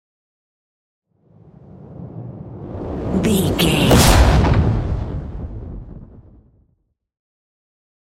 Dramatic whoosh to hit trailer
Sound Effects
Atonal
dark
futuristic
intense
tension